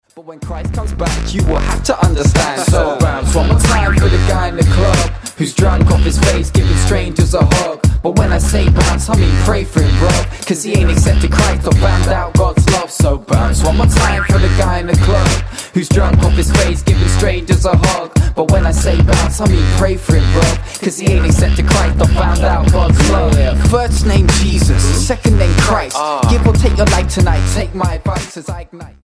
gospel rapper
Style: Hip-Hop